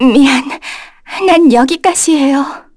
Cassandra-Vox_Dead_kr.wav